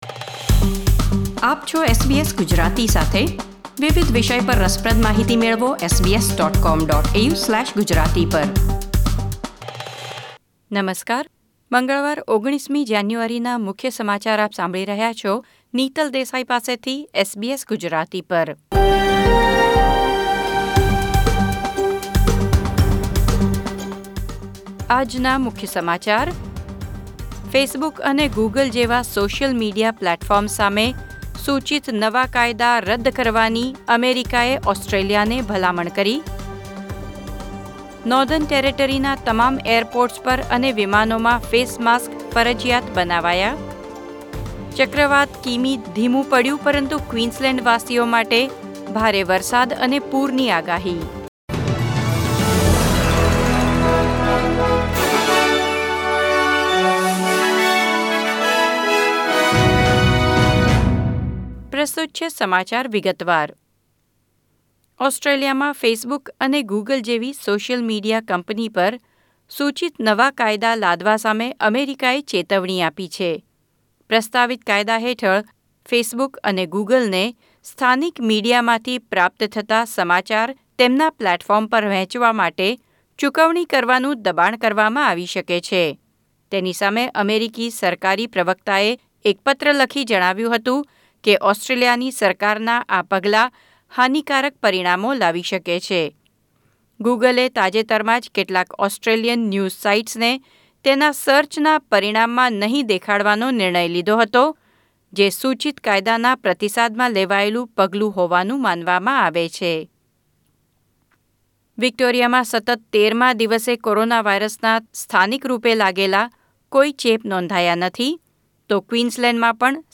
SBS Gujarati News Bulletin 19 January 2021